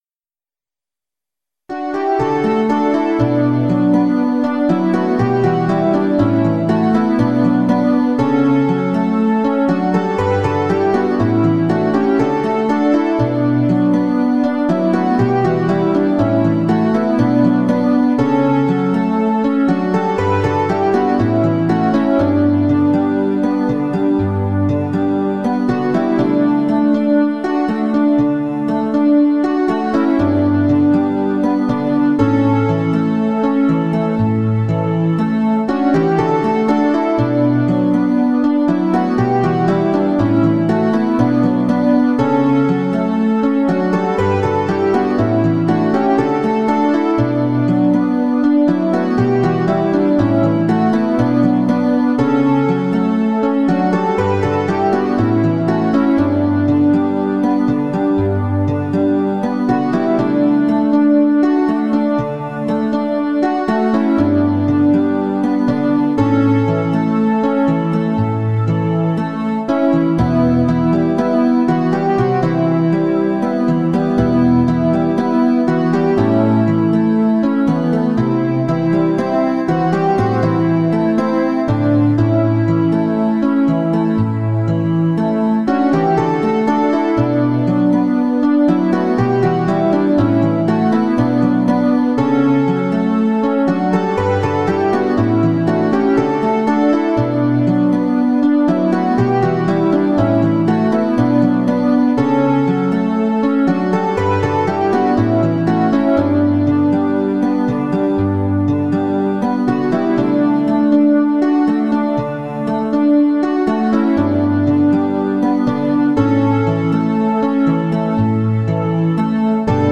Psalm 111 (V2). I Will Praise You Lord. An upbeat Psalm of Praise to a loving God.